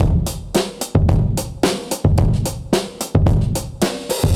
Index of /musicradar/dusty-funk-samples/Beats/110bpm/Alt Sound
DF_BeatA[dustier]_110-01.wav